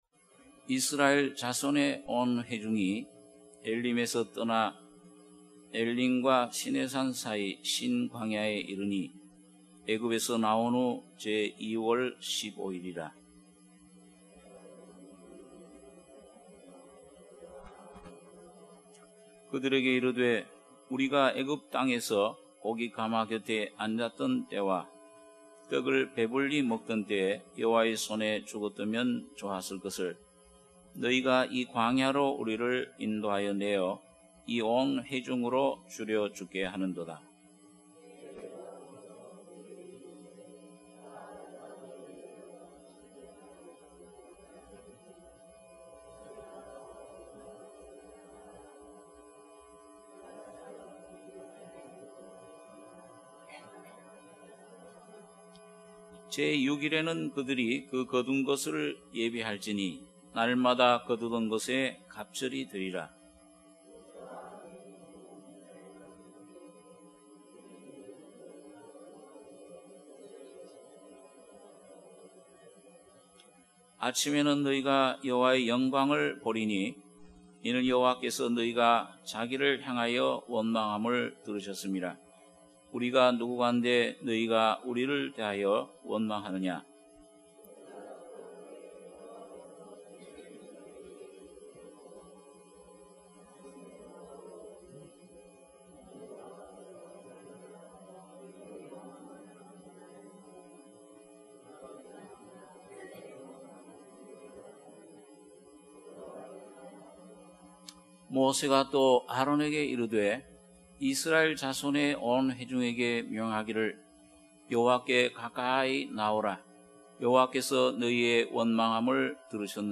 주일예배 - 출애굽기 16장 01-12절